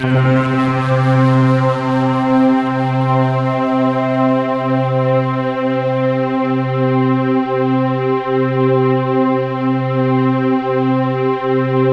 POLYSYNTC3-L.wav